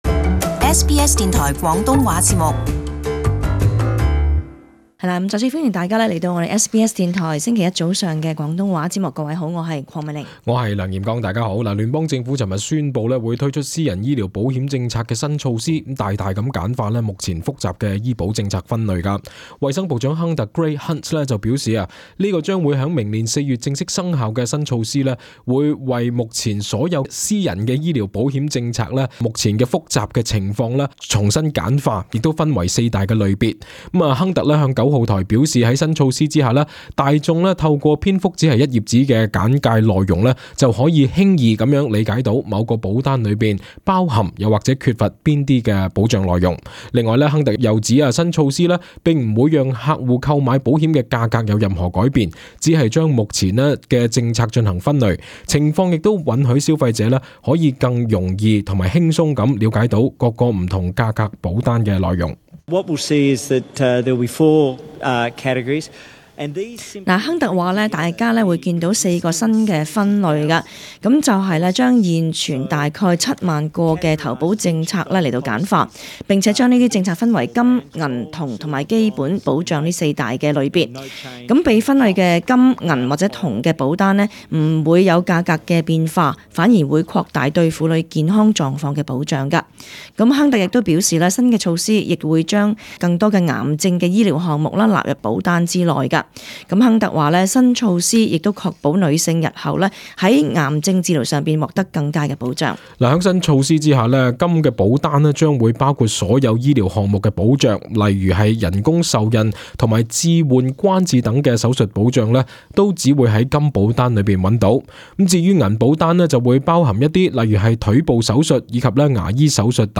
【時事報導】聯邦政府宣布簡化私人醫保制度